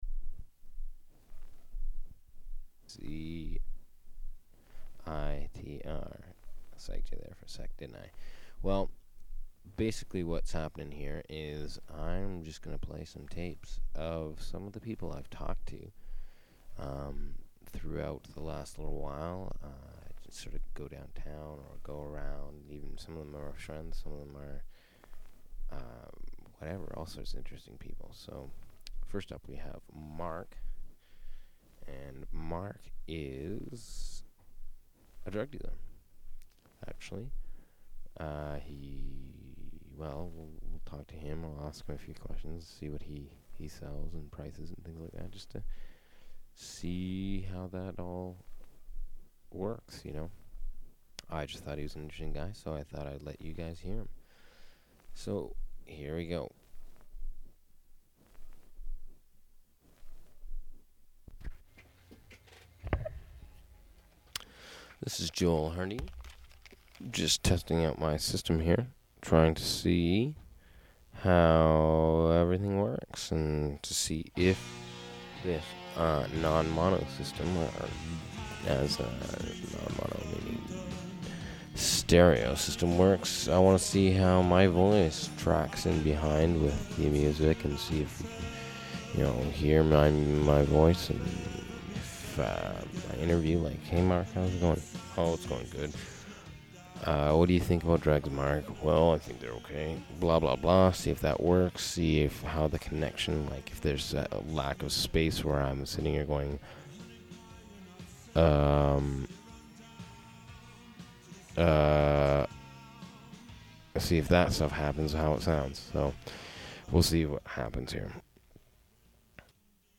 Recording of a mock interview on the topic of drug dealing in Vancouver's Downtown Eastside.